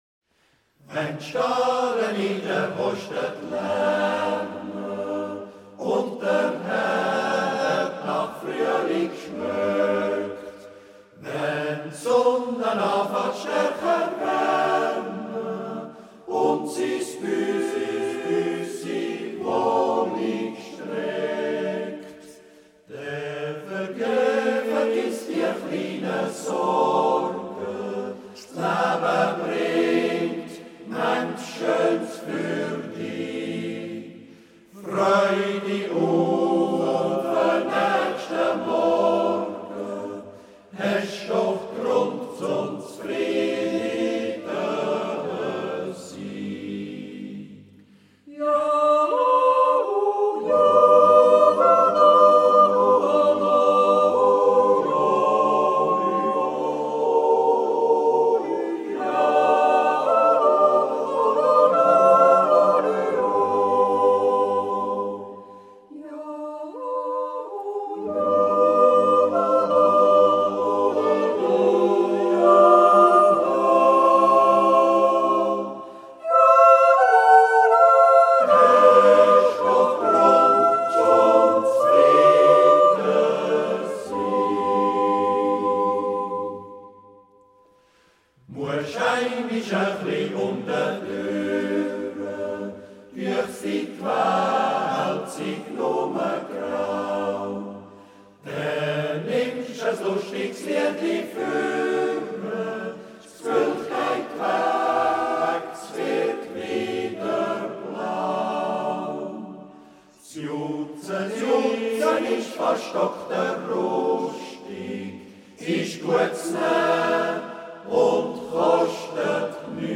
A Swiss National Yodeling Festival